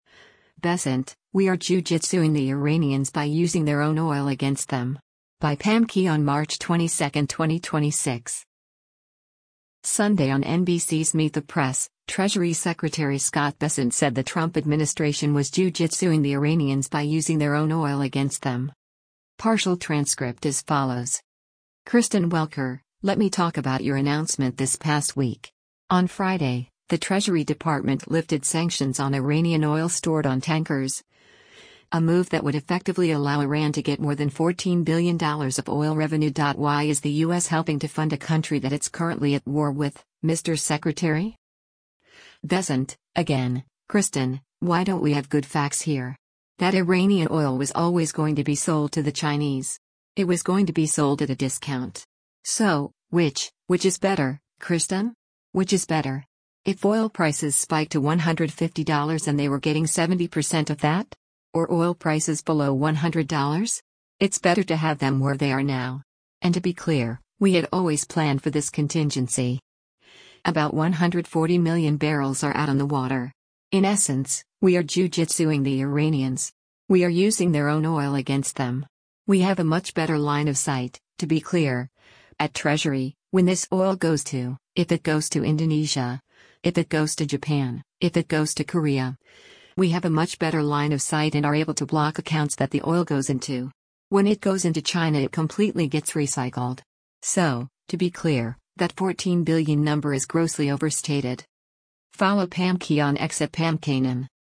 Sunday on NBC’s “Meet the Press,” Treasury Secretary Scott Bessent said the Trump administration was “jiu-jitsuing the Iranians” by “using their own oil against them.”